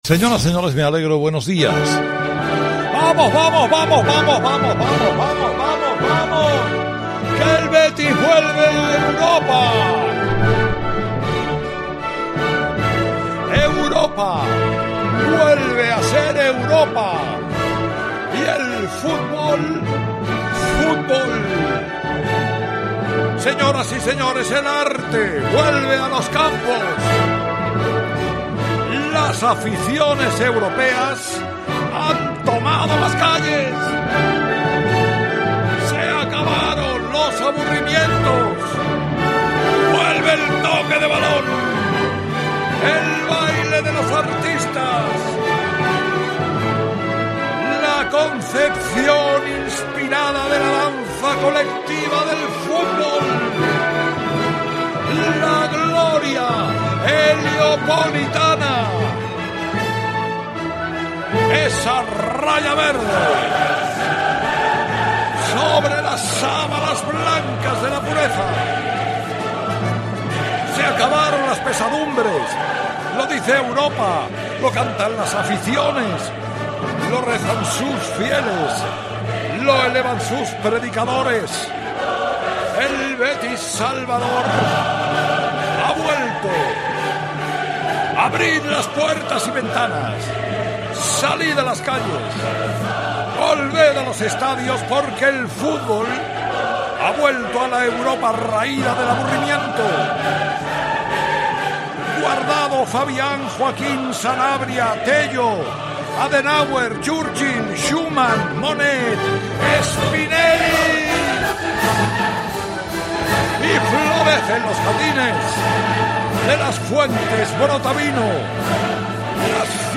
Esta mañana Carlos Herrera no podía contener su emoción tras el triunfo del Betis.
Además, a las ocho de la mañana, en su tradicional monólogo Carlos Herrera ha homenajeado de la siguiente forma a todos los jugadores del Betis: